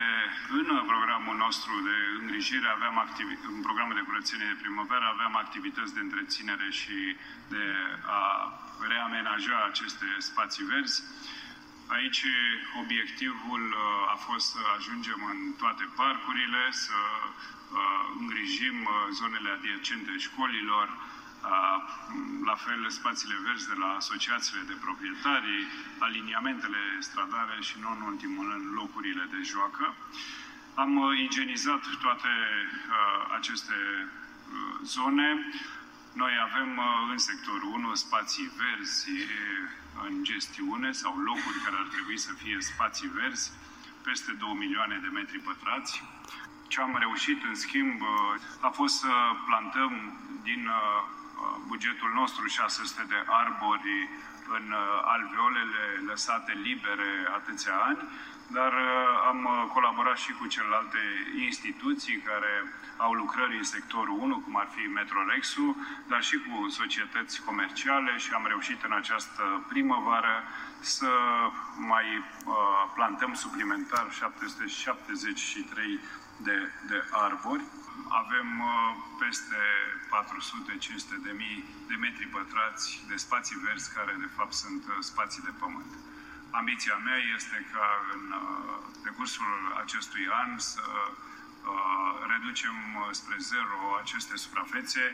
Până la finele acestui an, Primăria Sectorului 1 va reabilita aceste spații verzi deteriorate, a promis George Tuță, în plenul Consiliului Local din Banu Manta.